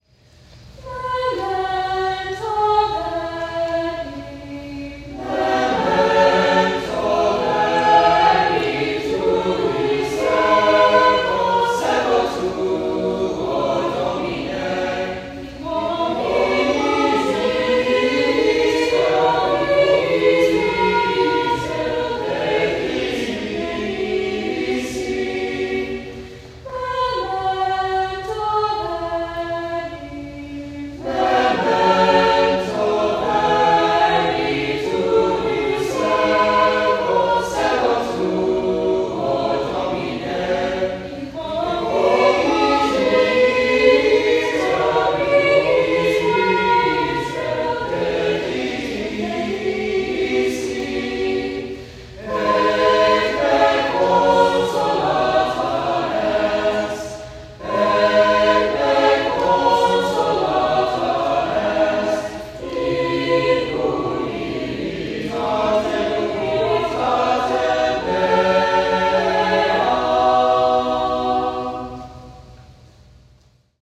The young girl sent us this live recording , taken with an iPhone. The piece she mentions (“Memento Verbi”) is part of a special collection Kevin Allen wrote for Soprano, Alto, and Bass called M ATRI D IVINAE G RATIAE .